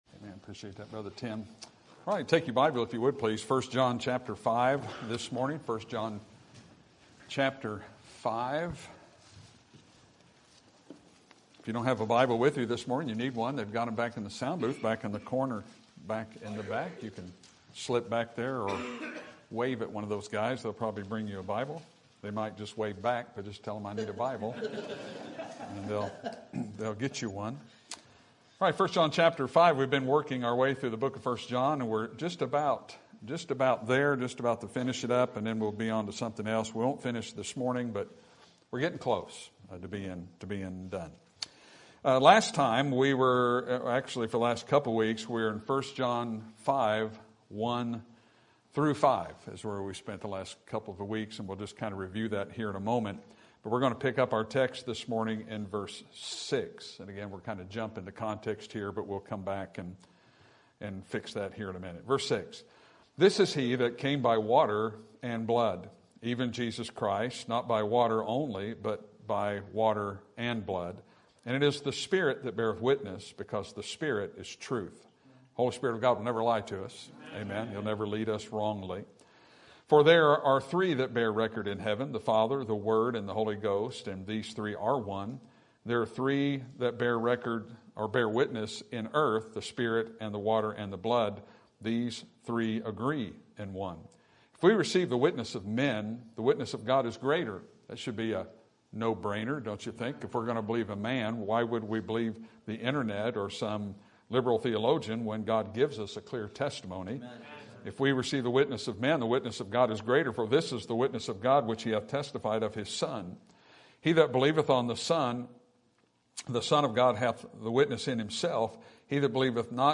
Sermon Topic: Book of 1 John Sermon Type: Series Sermon Audio: Sermon download: Download (23.36 MB) Sermon Tags: 1 John Love Christian Salvation